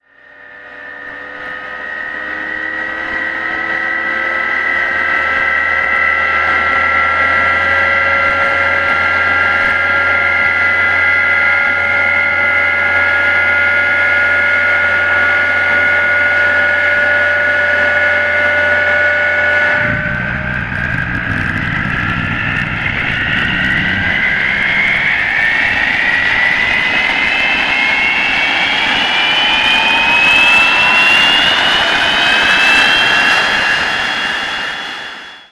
vulcan-start.wav